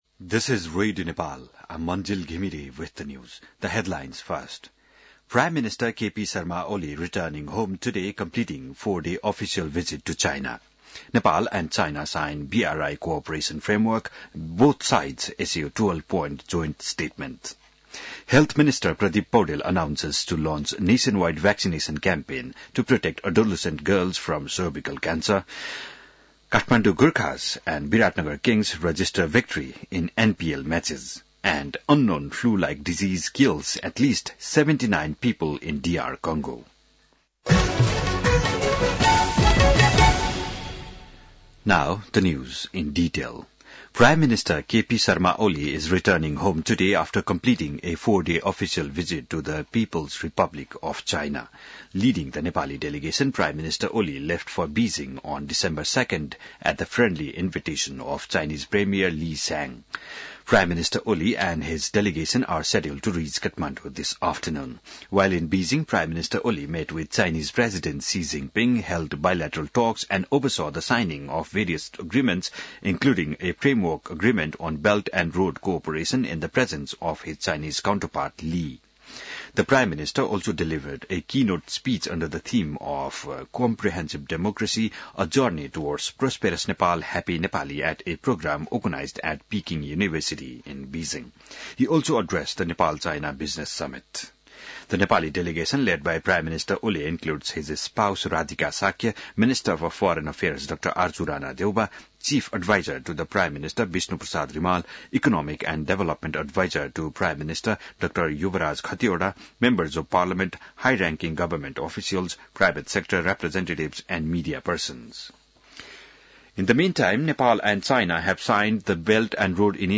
An online outlet of Nepal's national radio broadcaster
बिहान ८ बजेको अङ्ग्रेजी समाचार : २१ मंसिर , २०८१